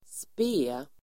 Uttal: [spe:]